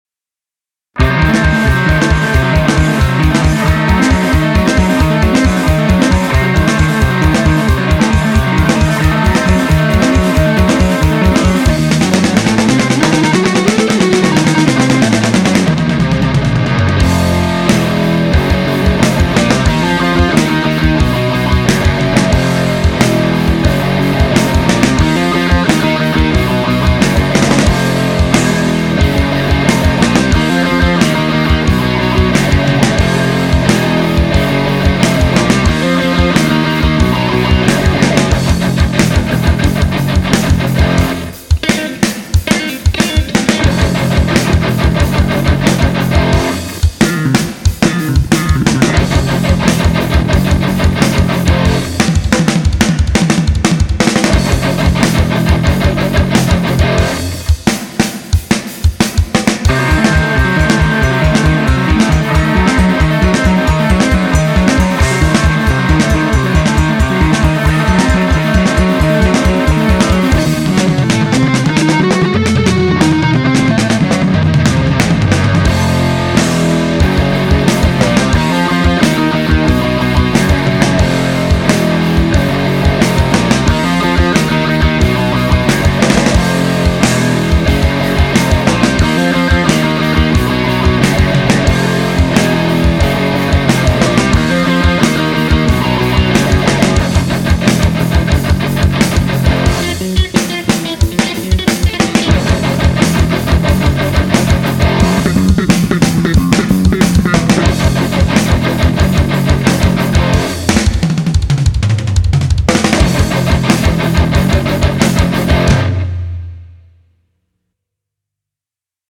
EstiloRock
Tem uma atmosfera sinistra e nos remete ao rock progressivo.